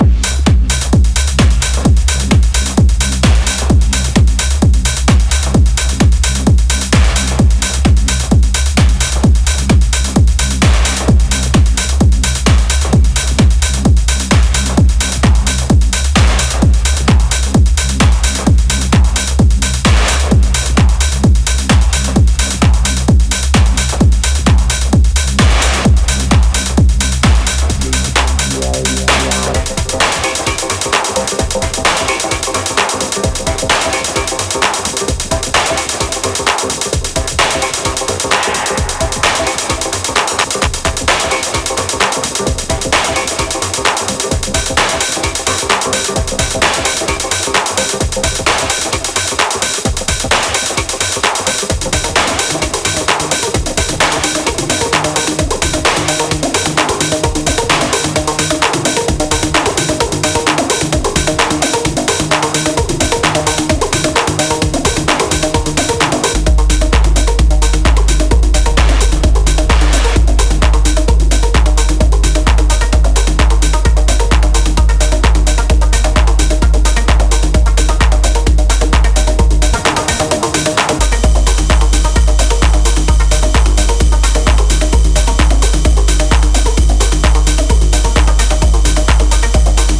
ジャンル(スタイル) TECHNO